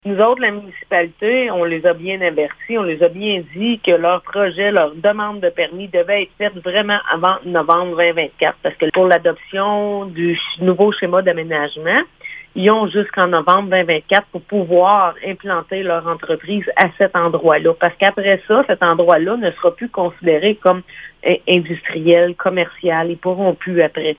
Le projet Gigaville qui est prévu à Déléage depuis 2021 est maintenant à la croisée des chemins alors qu’il reste moins d’un an avant la date butoir du 15 novembre. Les travaux doivent démarrer avant que le Schéma d’aménagement et de développement révisé de la MRC de la Vallée-de-la-Gatineau ne soit adopté. La mairesse de Déléage, Anne Potvin, précise pour quelle raison :